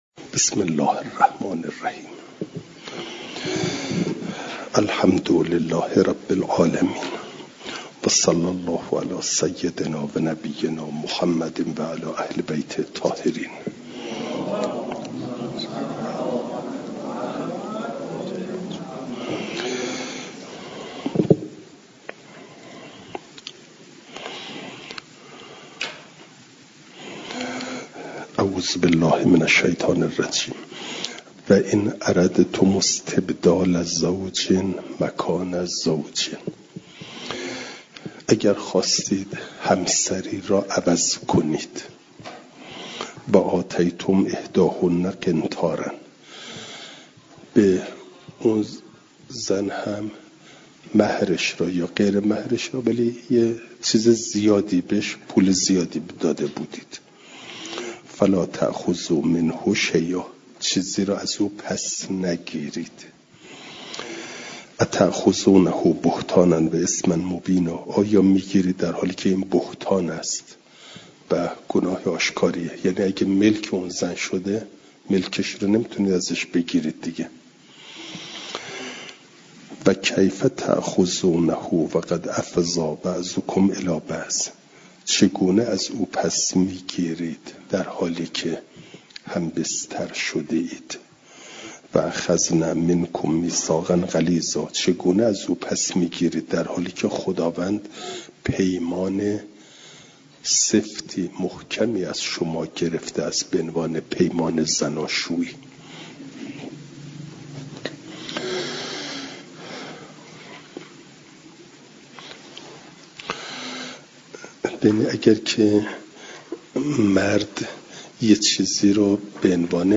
جلسه سیصد و پنجاهم درس تفسیر مجمع البیان